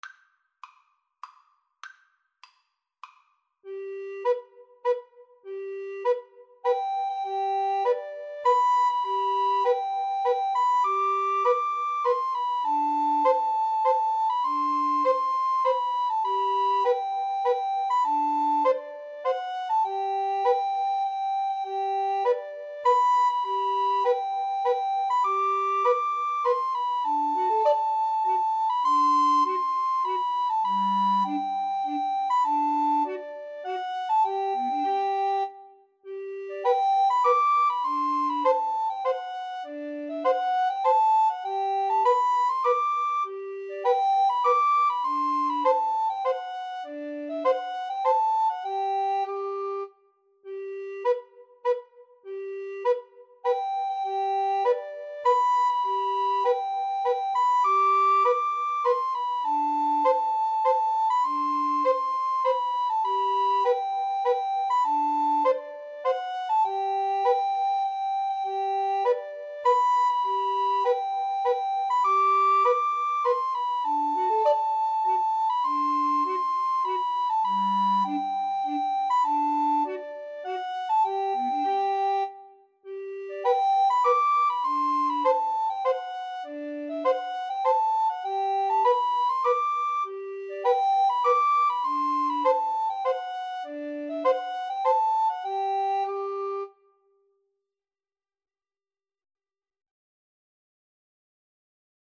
Free Sheet music for Recorder Trio
G major (Sounding Pitch) (View more G major Music for Recorder Trio )
Moderato
3/4 (View more 3/4 Music)